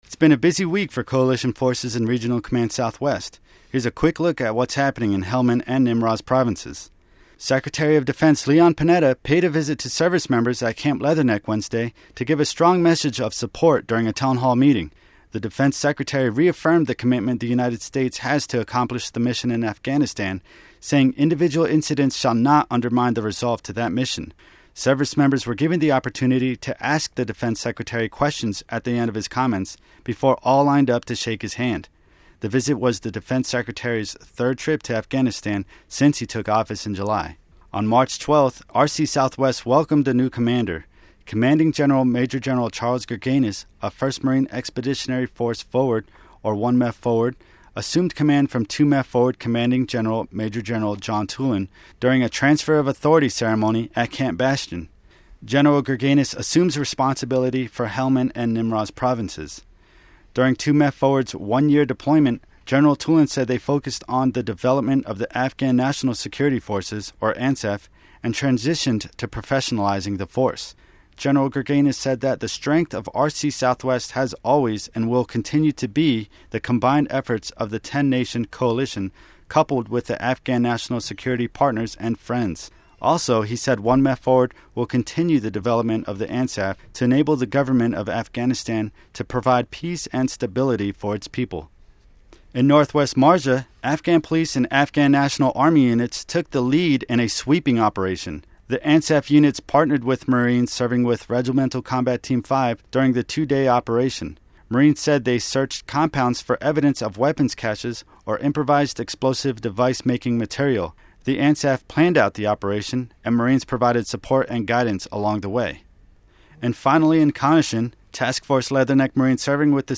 This is a recap done for British Forces Broadcast Service radio of some of the stories covered by the Regional Command Southwest public affairs team over the past week. Stories include the visit from Secretary of Defense Leon Panetta, the RC(SW) Transfer of Authority ceremony, and improving a school for Afghan children in Helmand province.